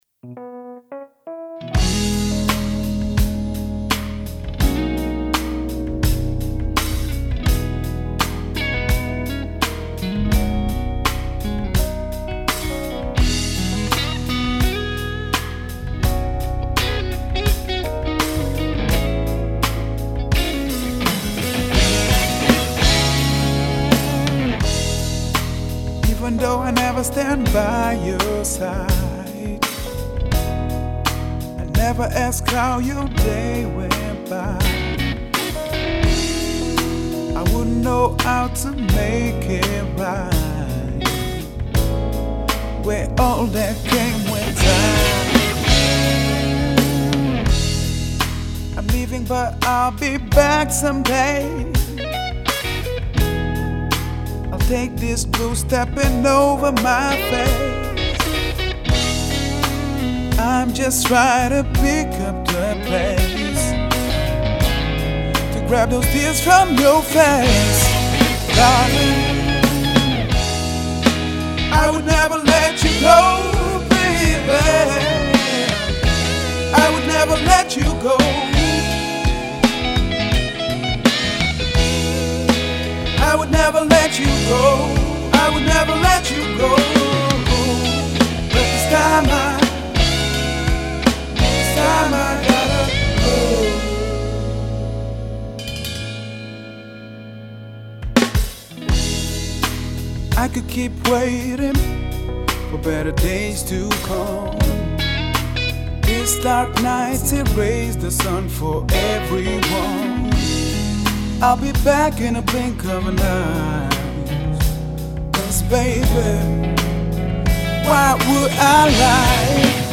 собрал только что тренировочный микс. в нем есть неочевидная фишка.